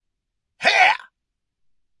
描述：男声呐喊'他啊'！
标签： 声乐 雄性 呼喊 语音
声道立体声